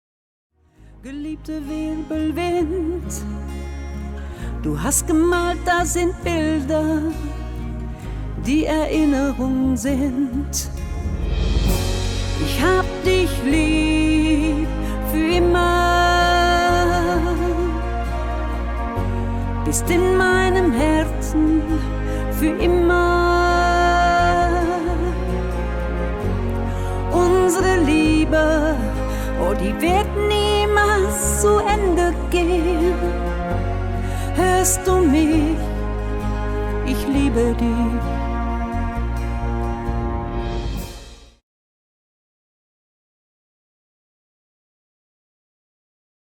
Trauerlied